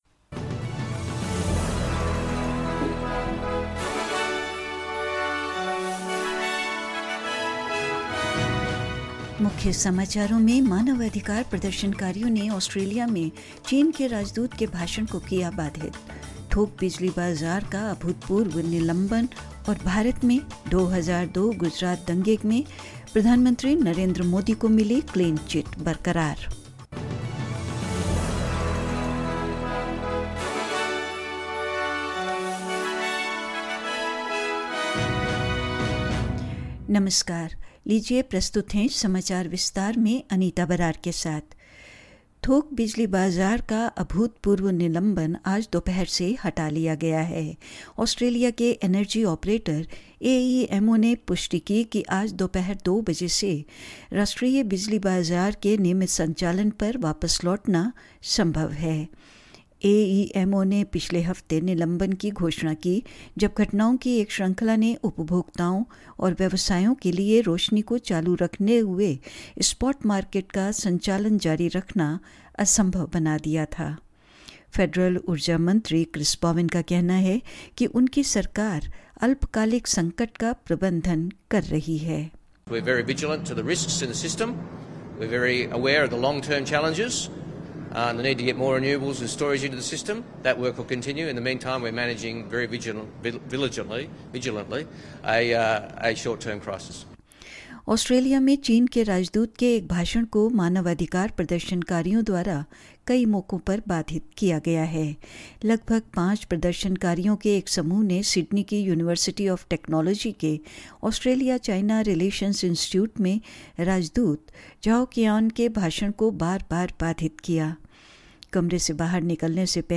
In this SBS Hindi bulletin: Human rights protestors disrupt a speech by China's ambassador to Australia; An unprecedented suspension of the wholesale electricity market has been lifted; In India, the Supreme Court upheld the Special Investigation Team's (SIT) clean chit to Mr Narendra Modi in the 2002 riots in Gujraat and more news.